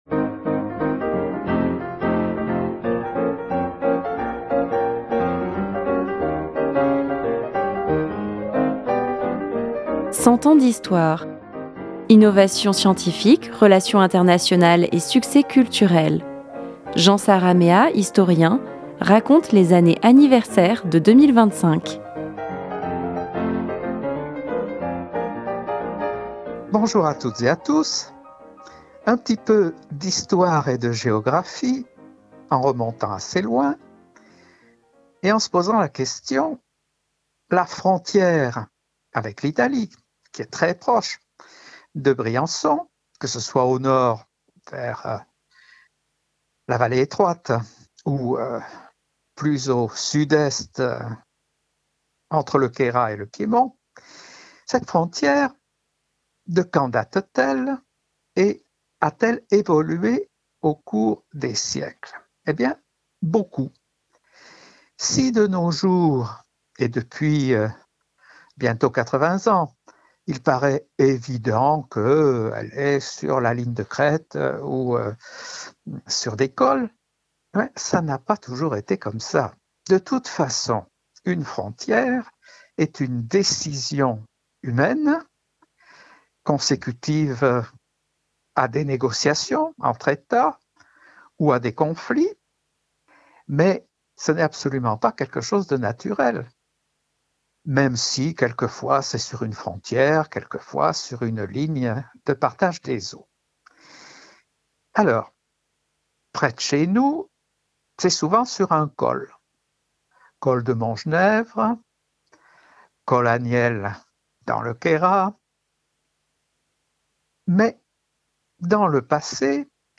historien géographe retraité vous raconte la petite histoire de la frontière France-Italie et ses anecdotes.